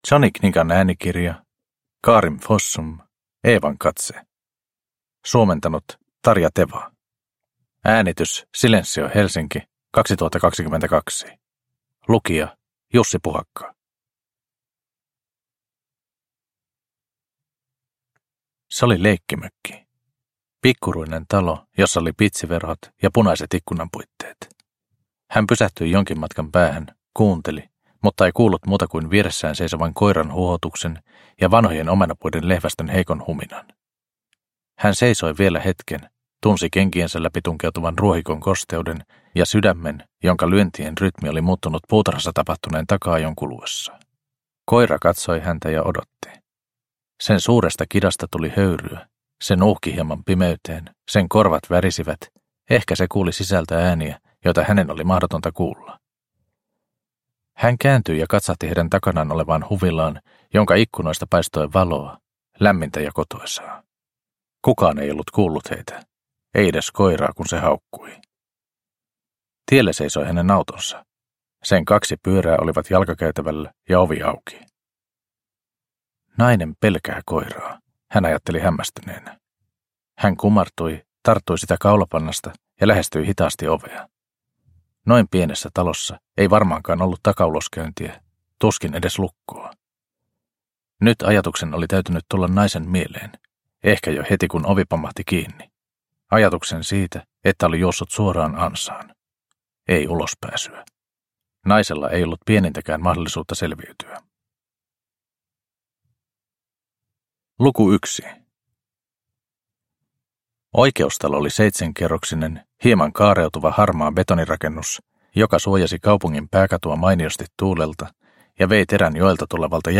Evan katse – Ljudbok – Laddas ner